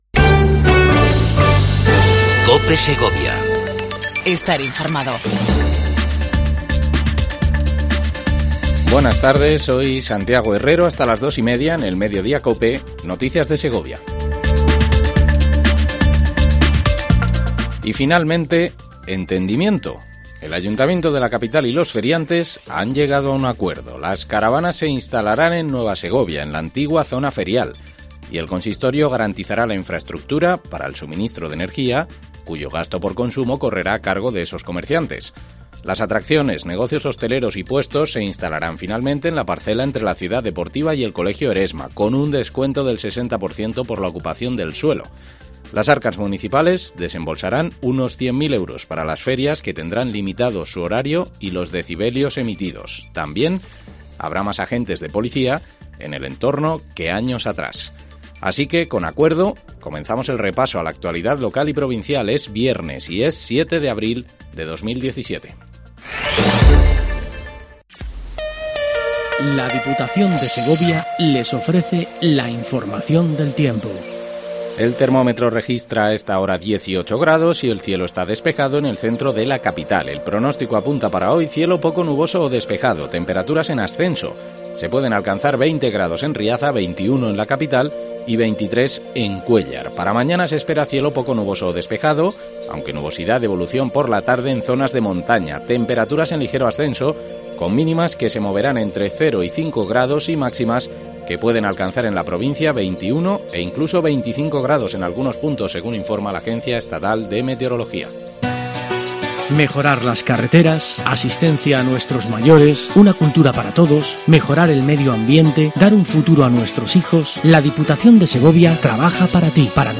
INFORMATIVO MEDIODIA COPE EN SEGOVIA 07 04 17